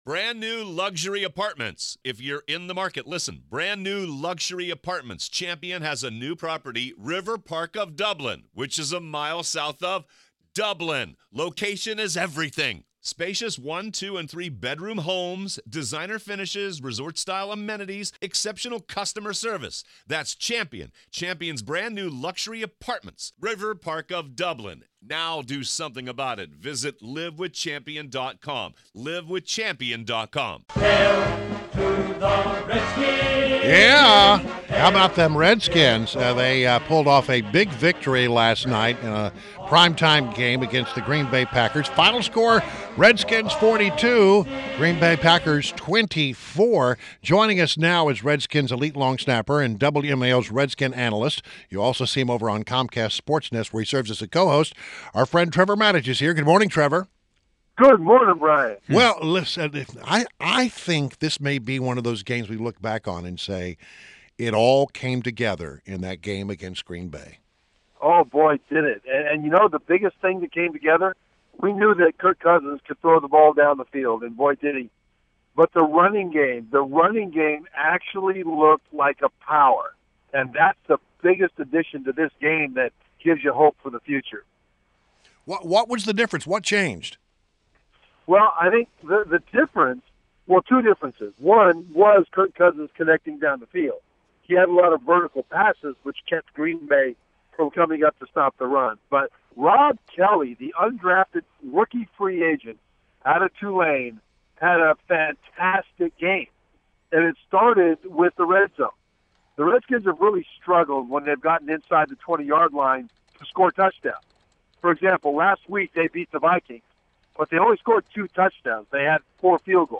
WMAL Interview - TREVOR MATICH - 11.21.16
INTERVIEW — TREVOR MATICH — Redskins elite long snapper, WMAL’s Redskins analyst and Comcast SportsNet co-host